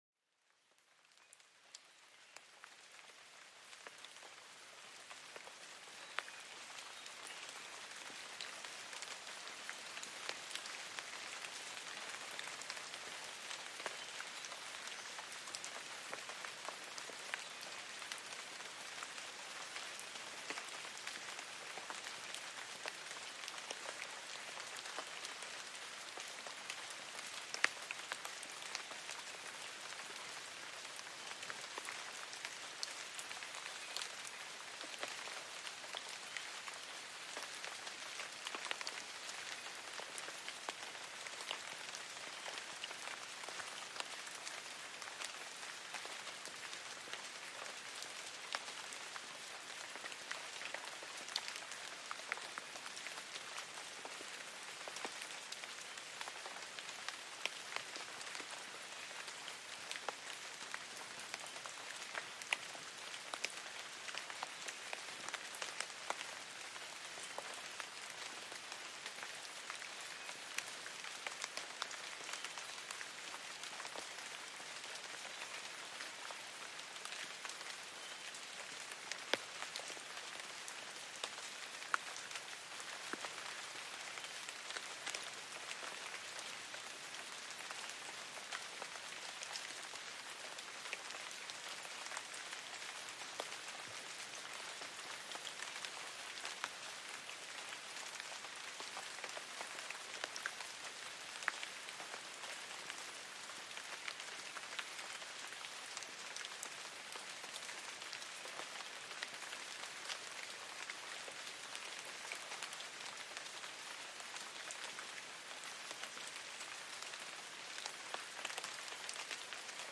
Lluvia Relajante en el Bosque - Relajación y Serenidad Garantizadas